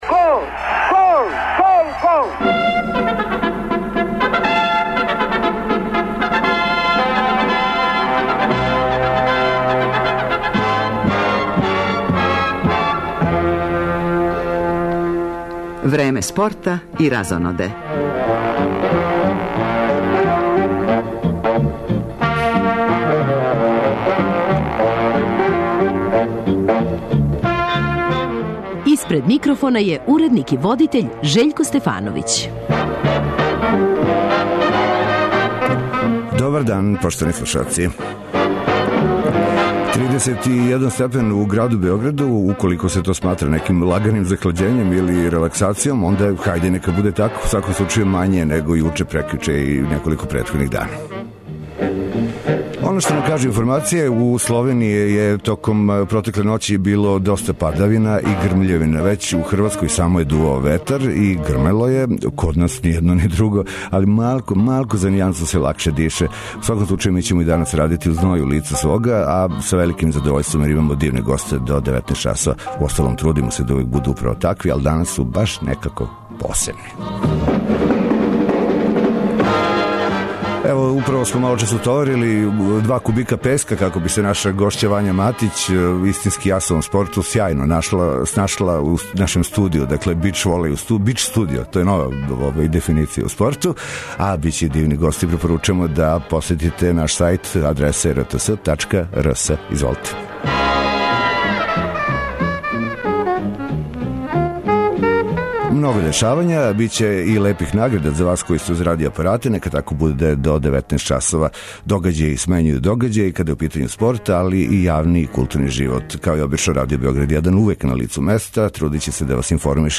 Гост емисије је Љубинко Друловић, селектор јуниорске репрезентације у фудбалу, која се недавно окитила титулом најбоље селекције на Првенству Европе.
Репортери емисије Време спорта и разоноде јављаће се са утакмице Гран при такмичења у одбојци за даме, између Србије и Алжира, као и са утакмица 1. кола новог издања Супер лиге Србије.